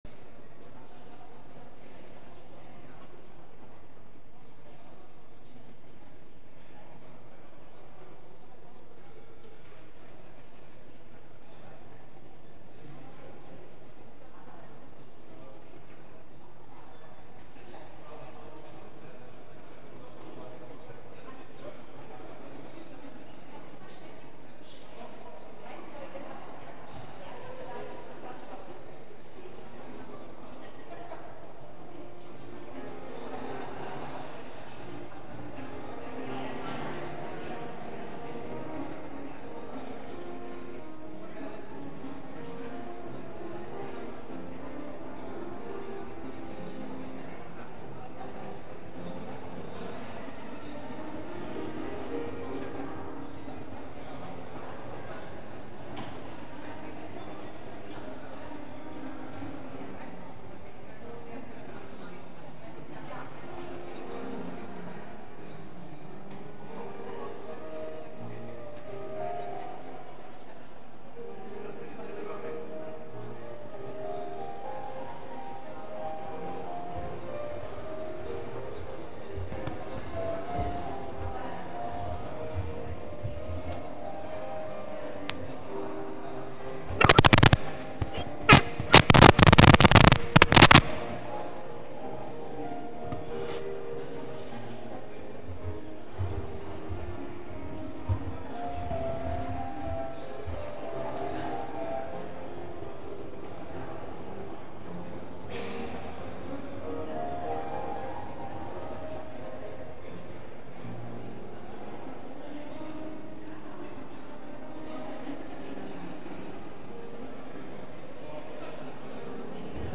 Culte à l’Oratoire du Louvre
Avec la participation exceptionnelle du Chœur de la Cathédrale de Schleswig